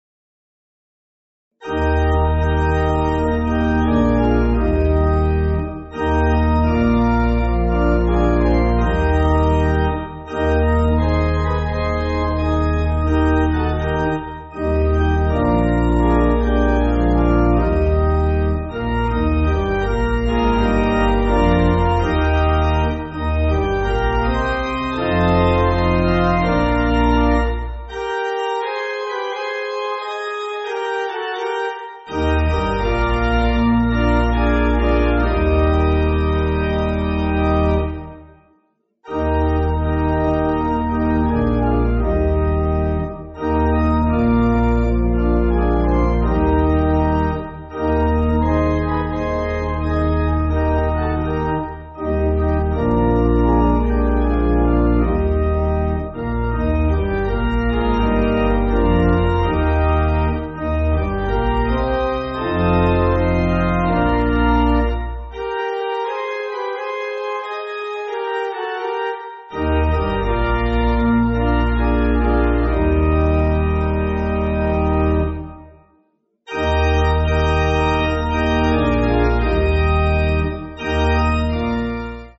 (CM)   3/Eb